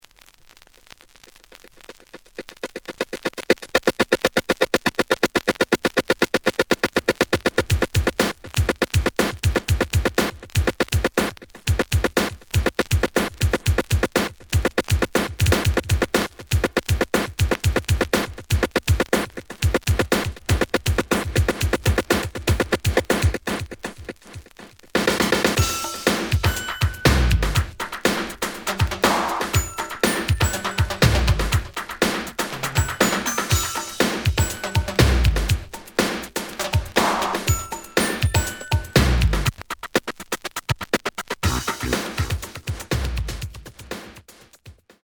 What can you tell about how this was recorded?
The audio sample is recorded from the actual item. Edge warp.